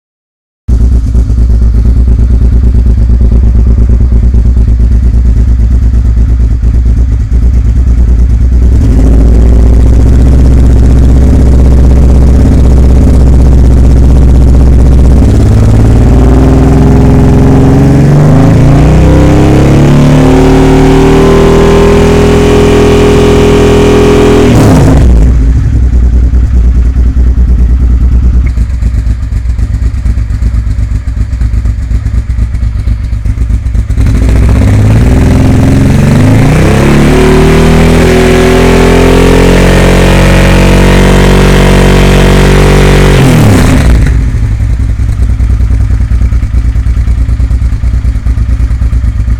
de eerste 28 sec is zonder dB killer